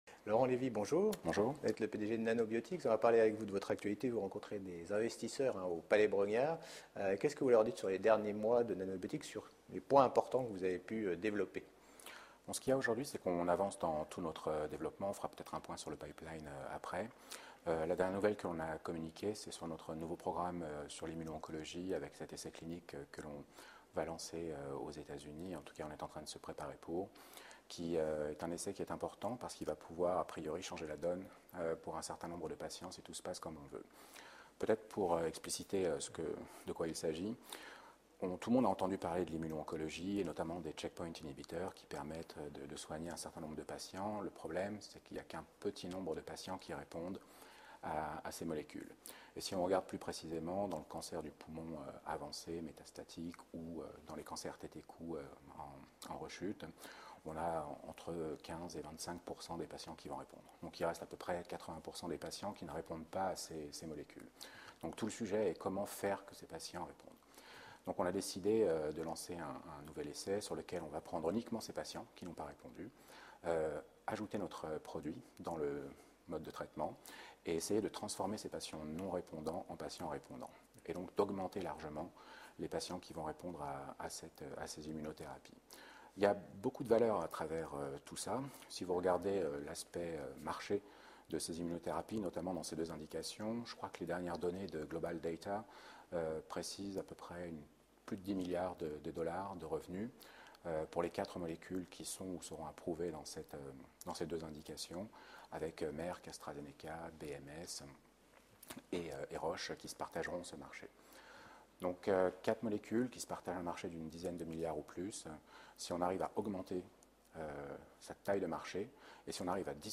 Rencontres de dirigeants à l’European Large & Midcap Event 2017 organisé par CF&B Communication.